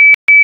LidarCalibration.ogg